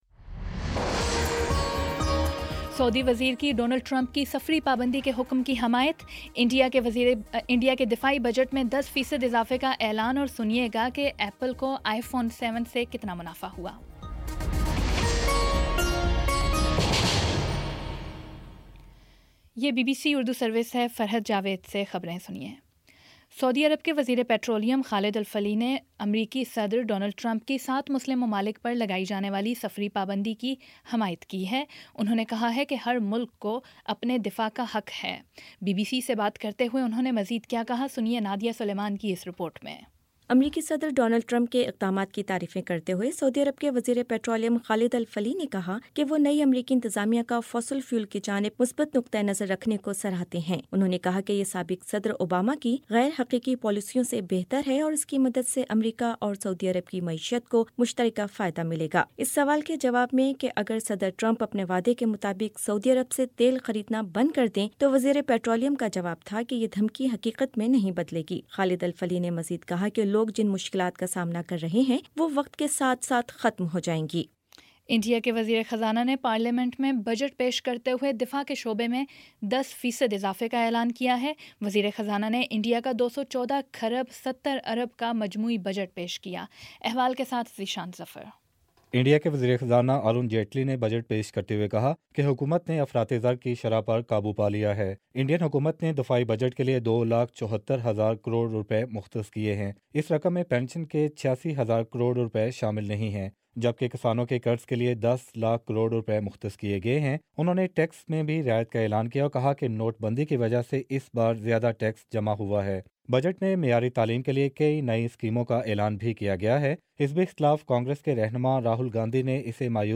فروری 01 : شام پانچ بجے کا نیوز بُلیٹن